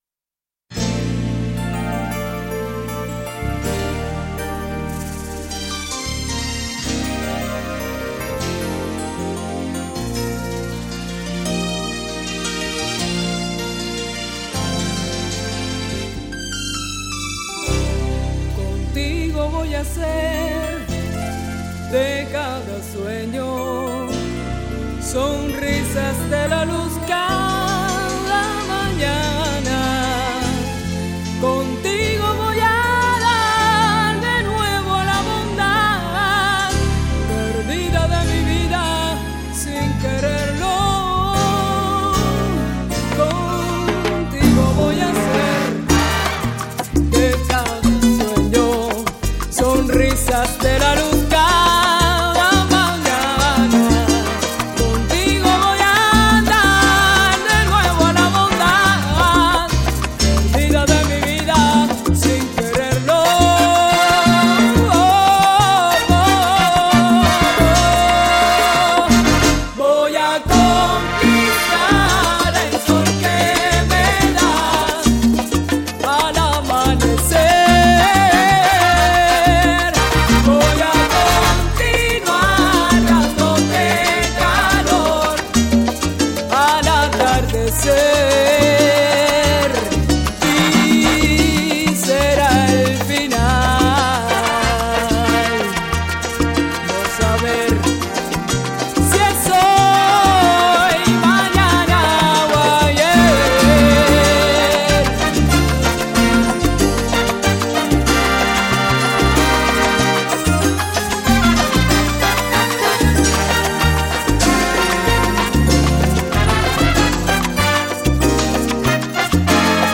Žánr: World music/Ethno/Folk
Súčasná kubánska popová interpretácie salsy